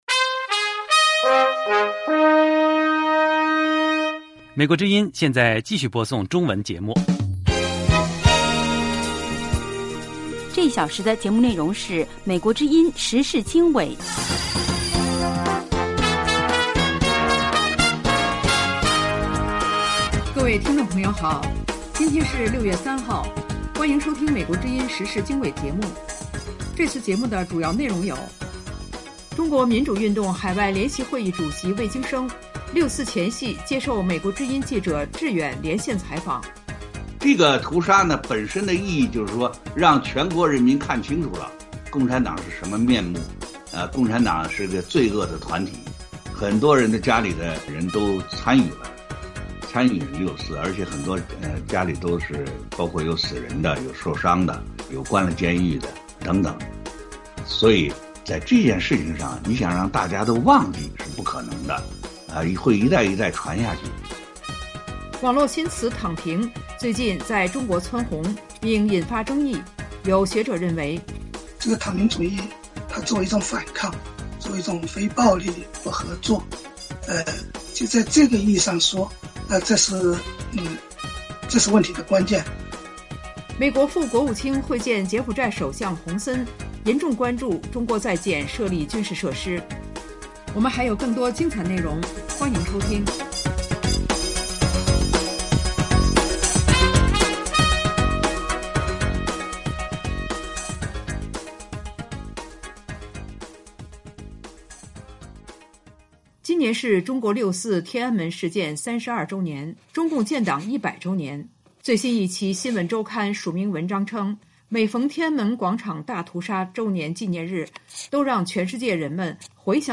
Window on the World Program of Voice of America Broadcasts its Interview with Mr. Wei Jingsheng: June 4th Massacre Made People Recognize the True Face of the Chinese Communist Party (CCP)